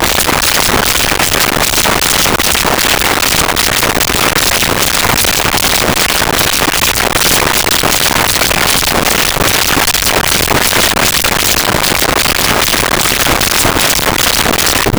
Heartbeat Fast
Heartbeat Fast.wav